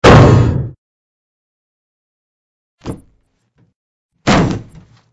AA_drop_bigweight.ogg